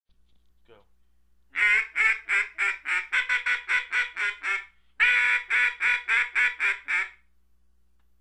Duck Greeting Call The Greeting Call is used after the Comeback call or whenever the ducks are close and looking at your blind.
This is similar to the Comeback call except it is shorter in duration, a little slower with a change in speed during the sequence.